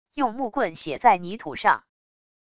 用木棍写在泥土上wav音频